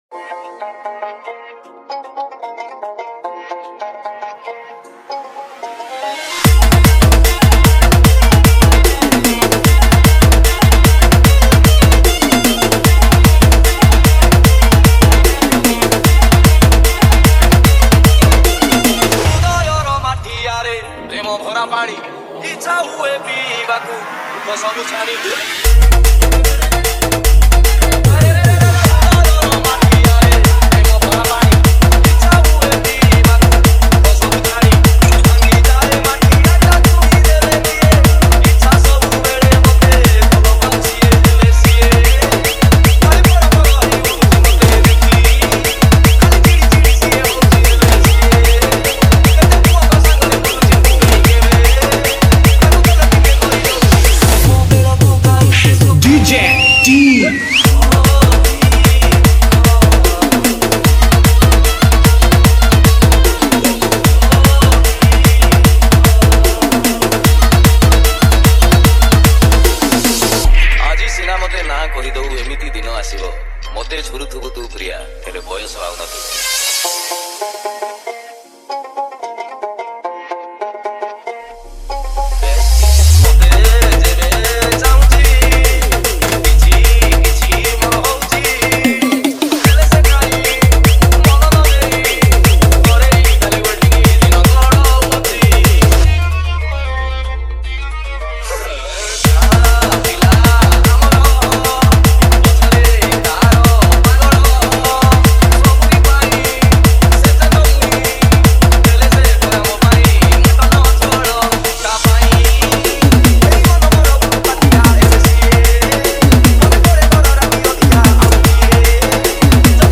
Category:  New Odia Dj Song 2022